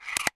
Drag&Drop-Drag.mp3